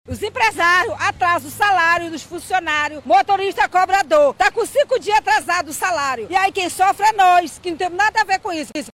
No Centro da cidade, passageiros ficaram revoltados com a situação, mas apoiaram a cobrança dos rodoviários, destaca uma usuária do Transporte Coletivo.